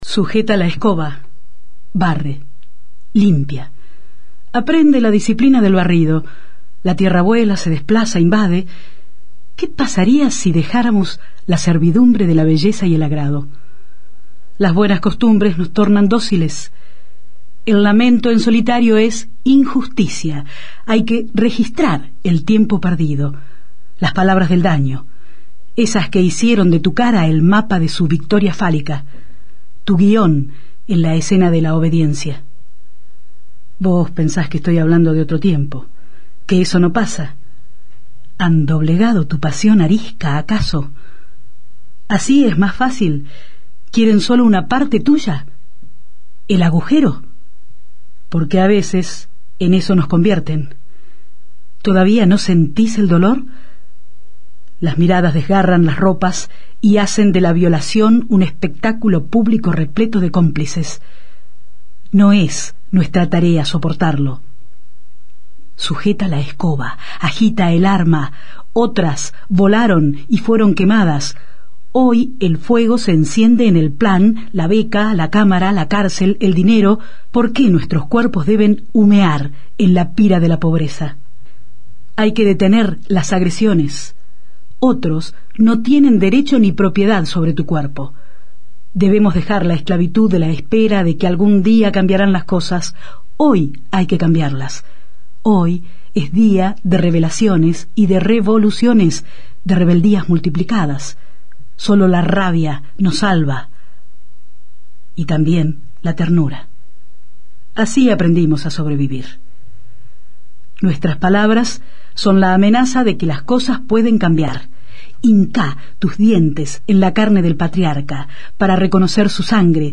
narrativa-radial-audio1.mp3